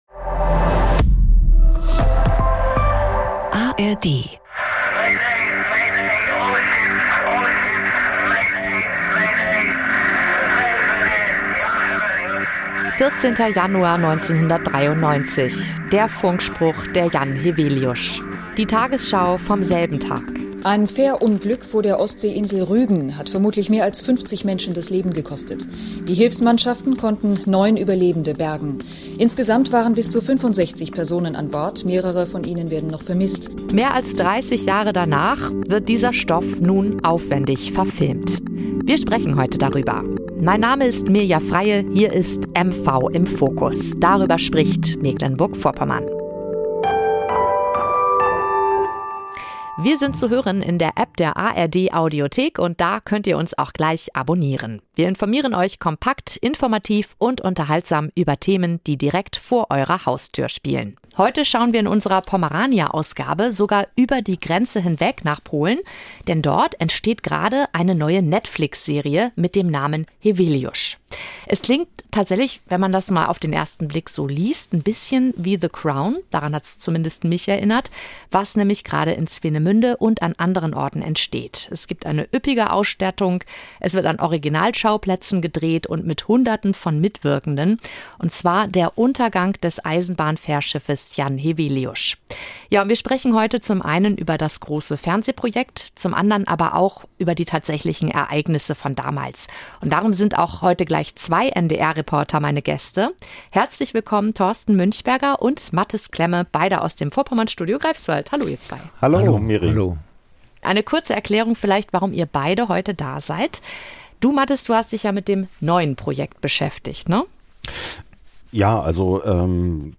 Das reale Untergangsdrama wird zur internationalen Thriller-Serie. Im Podcast schildert ein Reporter seine Erlebnisse damals.